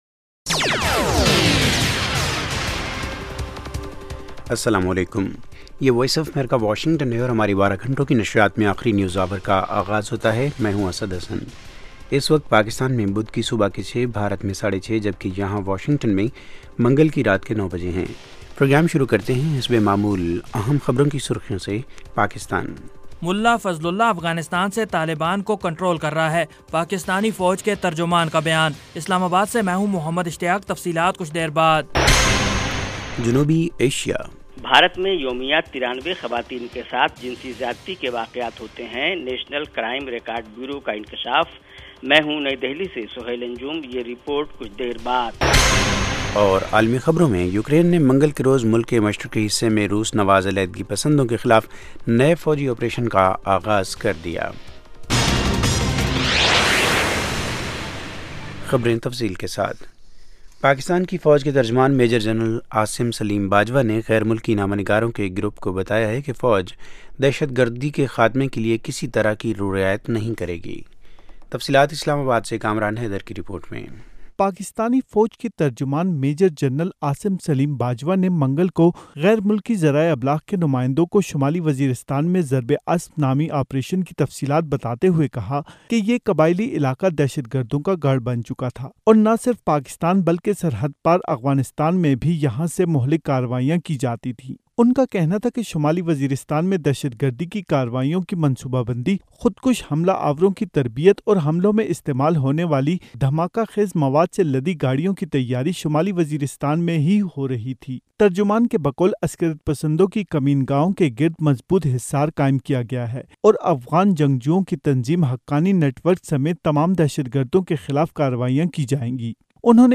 Urdu Headlines